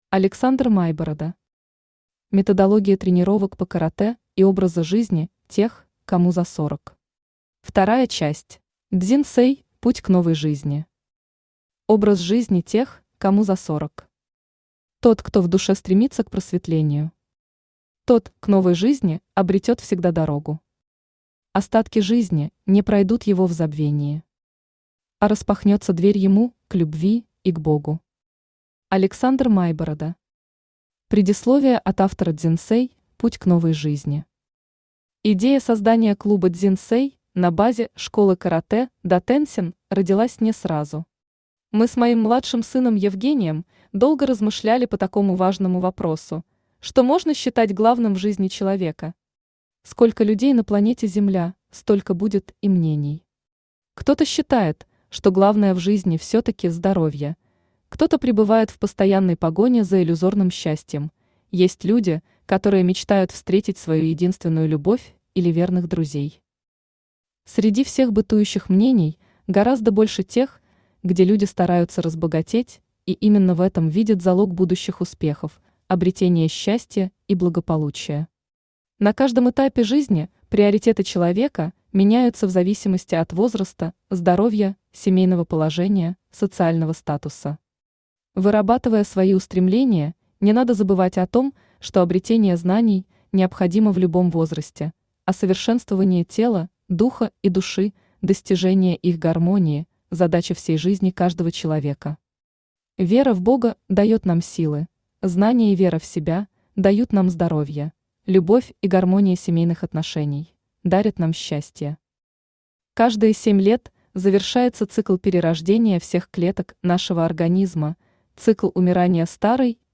Аудиокнига Методология тренировок по Каратэ и образа жизни тех, кому за сорок. 2 часть | Библиотека аудиокниг
Aудиокнига Методология тренировок по Каратэ и образа жизни тех, кому за сорок. 2 часть Автор Александр Алексеевич Майборода Читает аудиокнигу Авточтец ЛитРес.